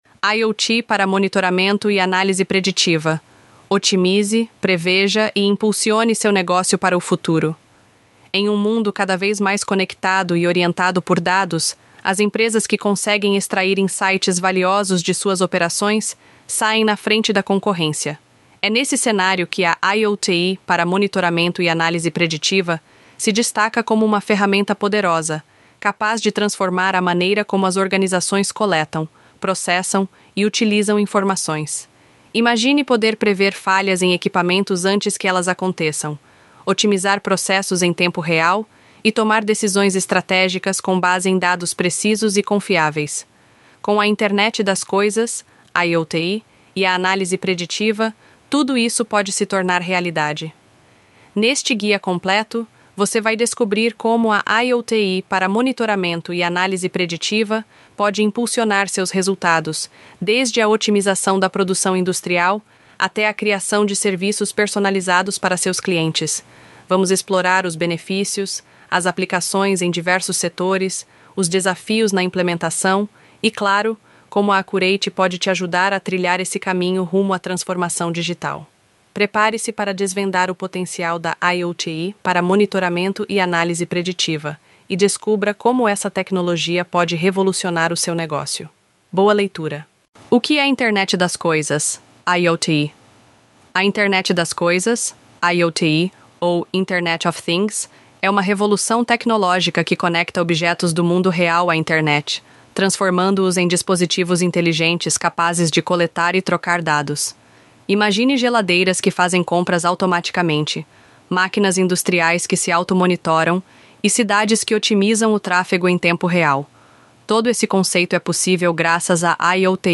Texto-sobre-Internet-das-Coisas-IoT-para-Monitoramento-e-Analise-de-Dados-Narracao-Avatar-Rachel-ElevenLabs.mp3